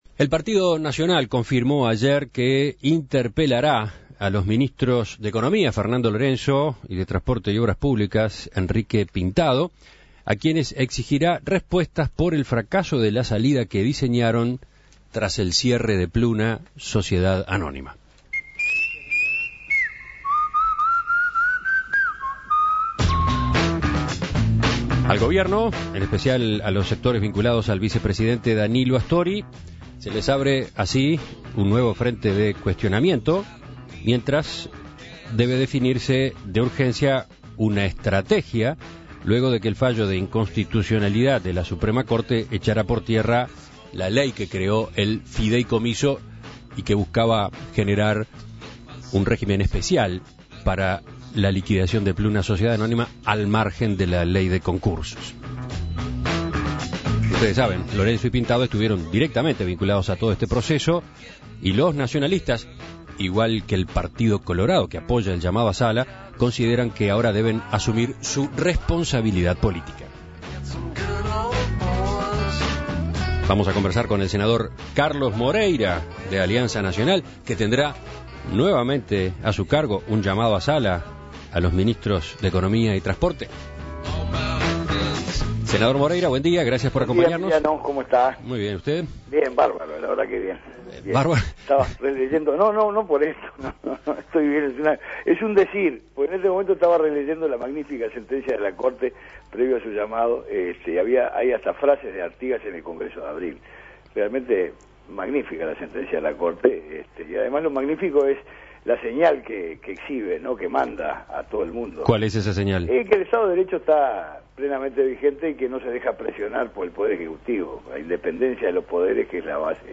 Para conocer más sobre este llamado a sala, En Perspectiva dialogó con el senador nacionalista Carlos Moreira, quien resaltó la independencia de poderes al manifestar que la SCJ no se ha dejado presionar por el Poder Ejecutivo.
Entrevistas